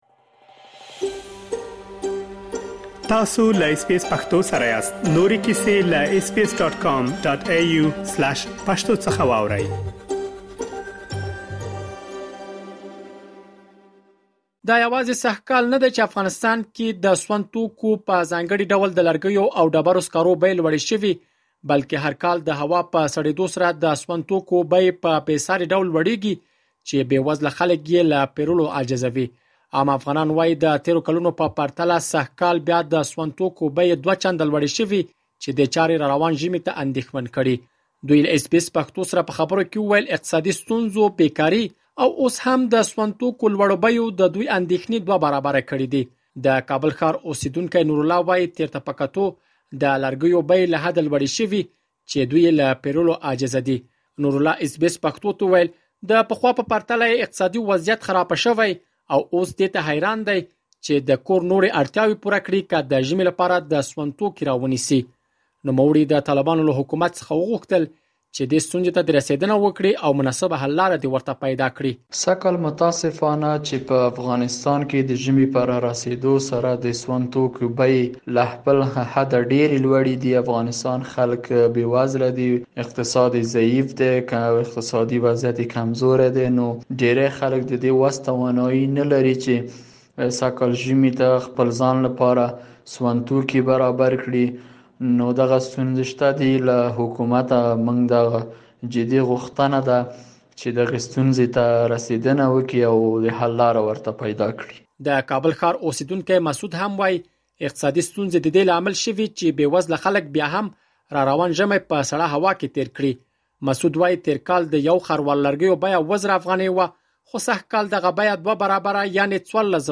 دوی له اس بې اس پښتو سره په خبرو کې وویل، اقتصادي ستونزو، بې کاري او اوس هم د سون توکو لوړو بیو د دوی اندېښنې دوه برابره کړې دي. مهرباني وکړئ لا ډېر معلومات دلته په رپوټ کې واوروئ.